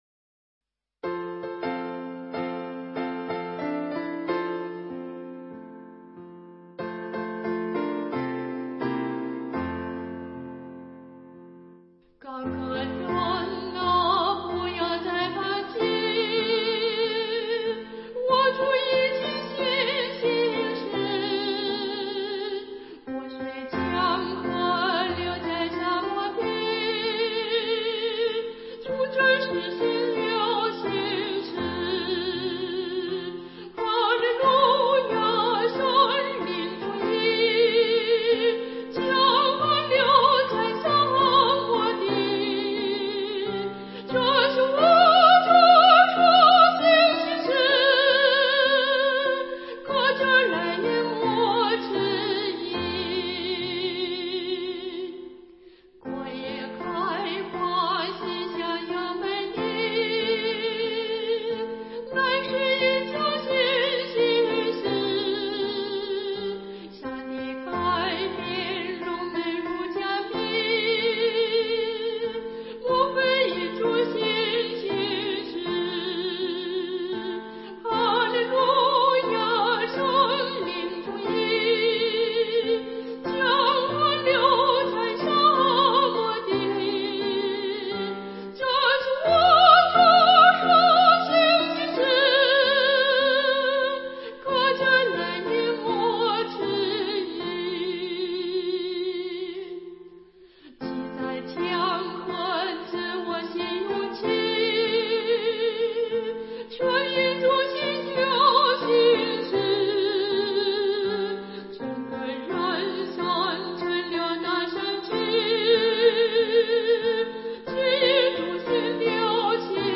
伴奏
本曲节奏较强。正歌部分沉着平稳，犹如军队前进。副歌则欢乐见激昂，从而形象地体现了歌曲的主题。